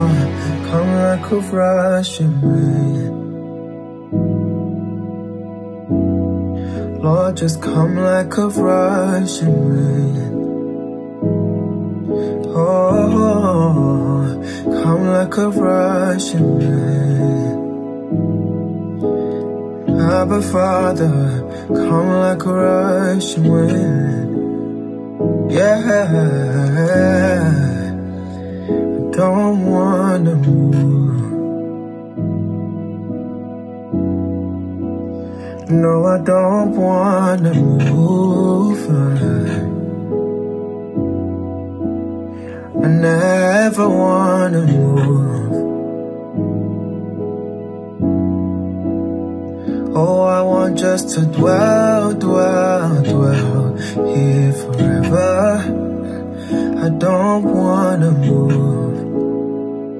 Ruach Elohim🌬: Mighty breath/ wind